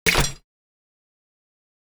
mixjump.wav